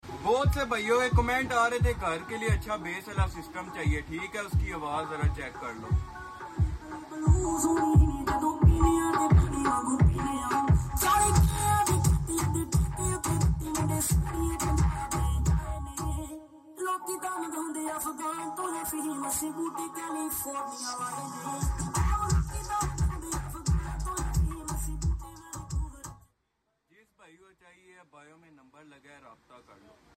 ❤HOME BASS SYSTEM❤ Sp4 Bass Sound Effects Free Download